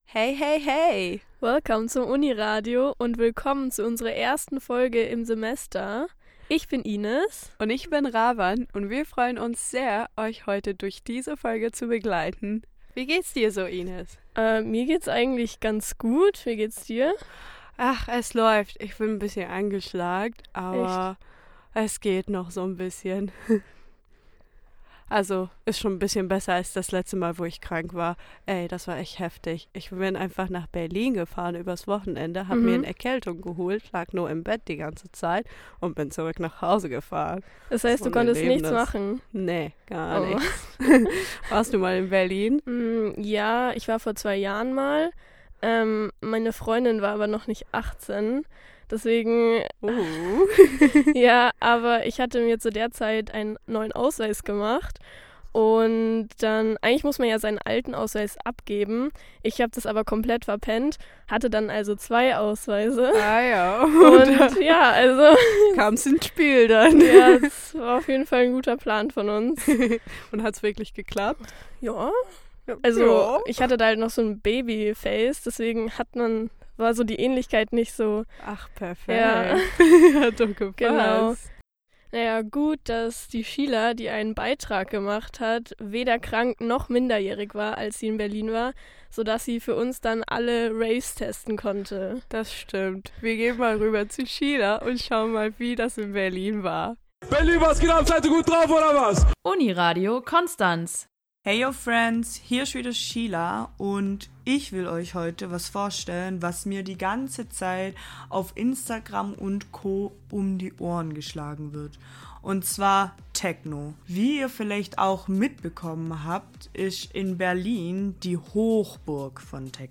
Diesmal mit einem Interview mit den Konstanz Pirates.